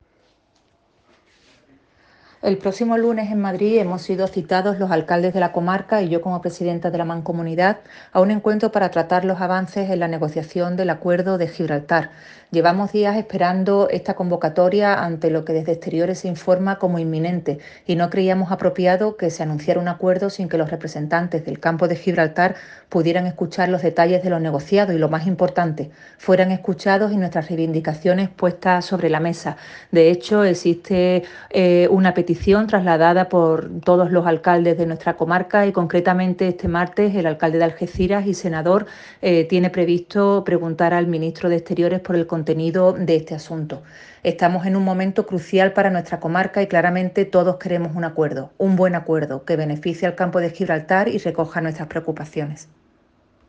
Audio presidenta de Mancomunidad